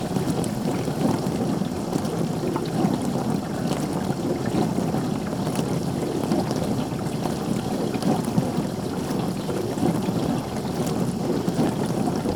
bruit-machine-eau.wav